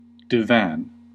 Ääntäminen
Synonyymit couch sofa Ääntäminen US UK : IPA : /dɪˈvæn/ US : IPA : /ˈdaɪ.væn/ Haettu sana löytyi näillä lähdekielillä: englanti Käännös Konteksti Substantiivit 1.